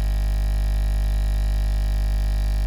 aquarium_buzz.ogg